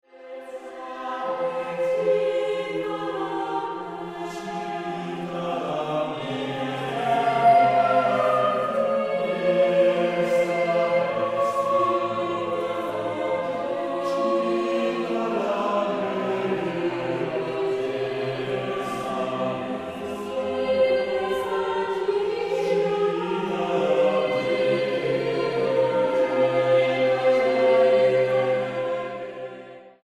Música Barroca Mexicana